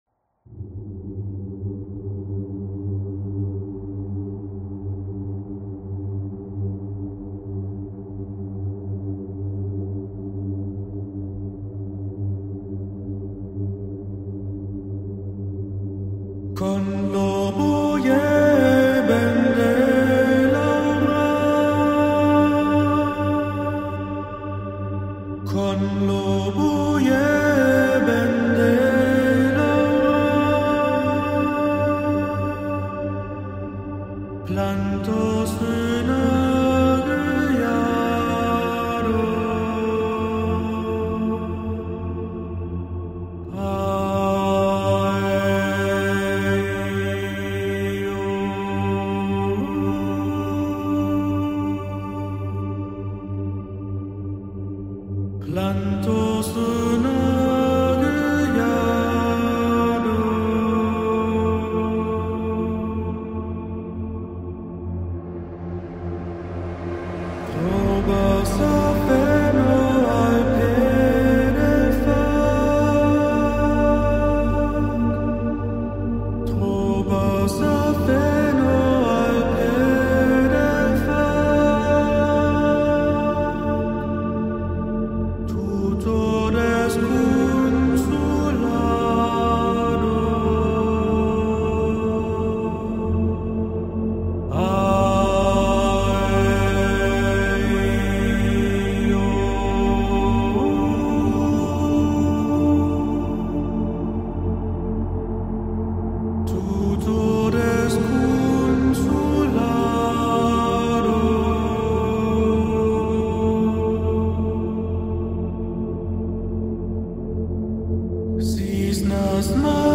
Chant of the Gnostics_ Lo ….mp3 📥 (7.12 MB)